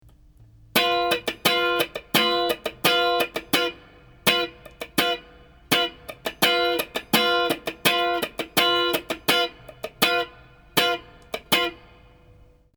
スタッカートをするのか、八分音符を弾ききるのかなど「音符の長さ」を明確にイメージしながら弾いていきましょう。